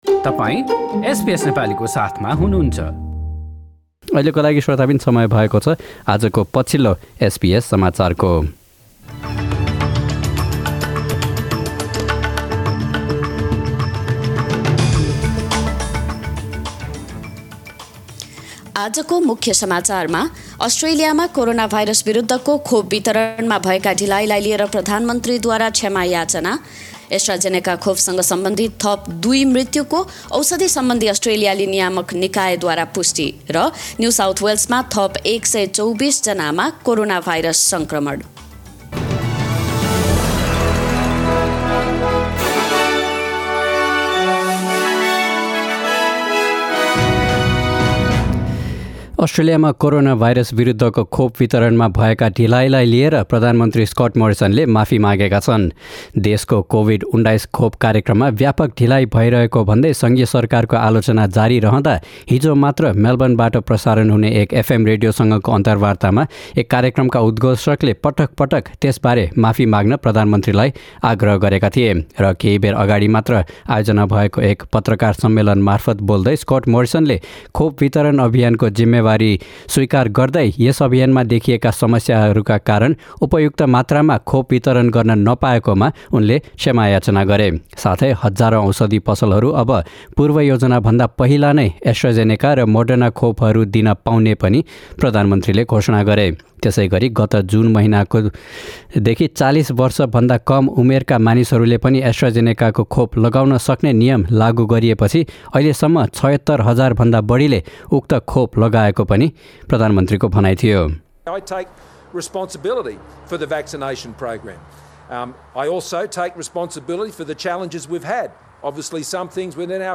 एसबीएस नेपाली अस्ट्रेलिया समाचार: बिहीवार २२ जुलाई २०२१